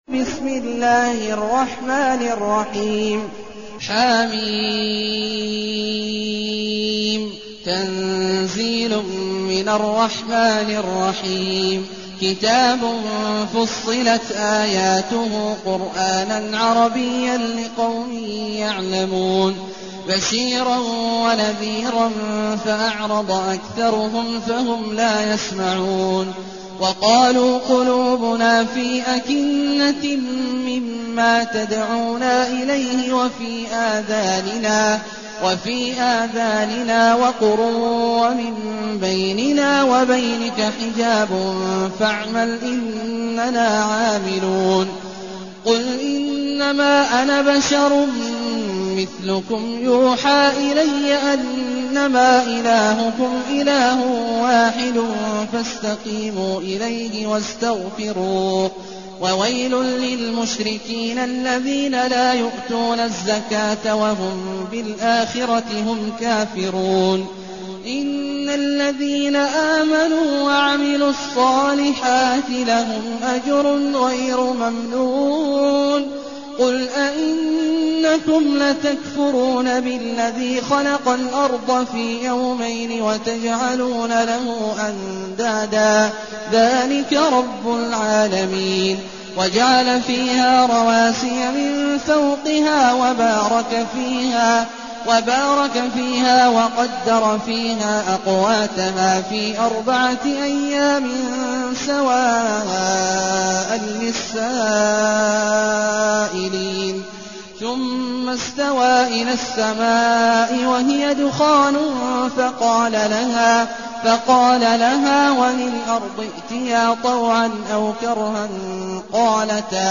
المكان: المسجد الحرام الشيخ: عبد الله عواد الجهني عبد الله عواد الجهني فصلت